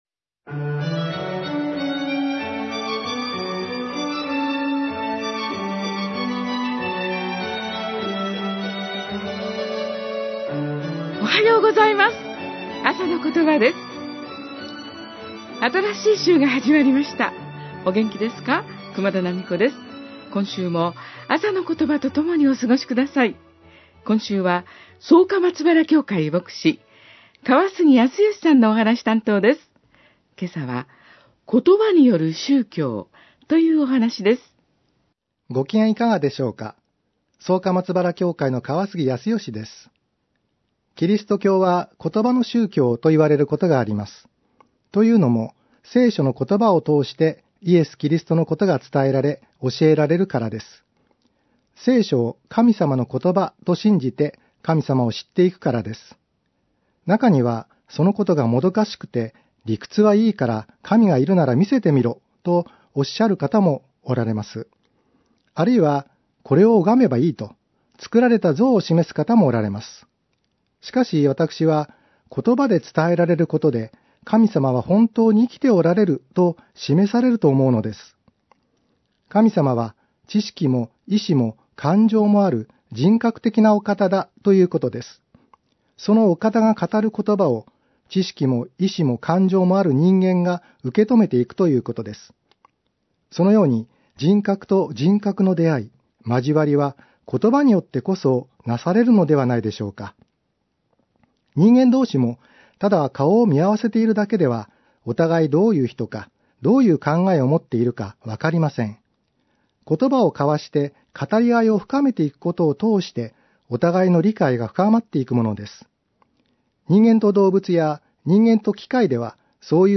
メッセージ： 言葉による宗教